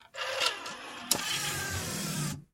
Звуки CD-плеера
Звук включения диска в CD-плеере